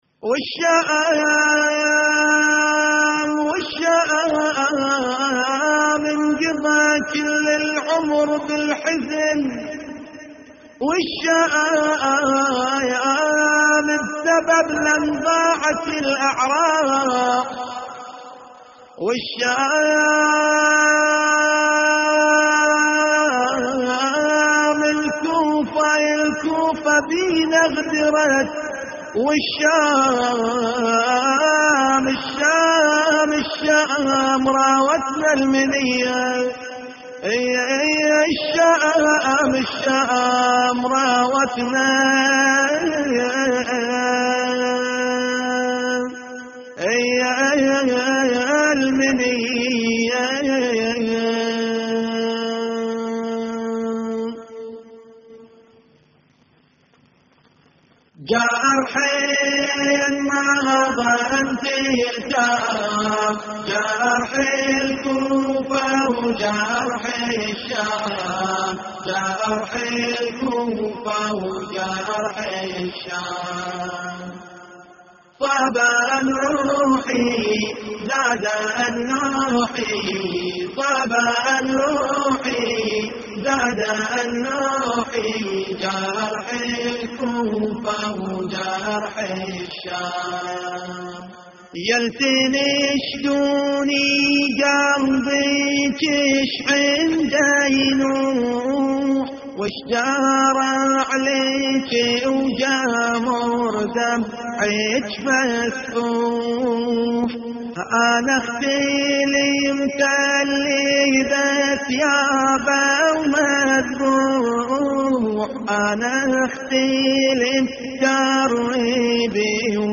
والشام انقضى كل العمر بالحزن والشام (نعي) + جرحين ما ظن تلتام
اللطميات الحسينية
استديو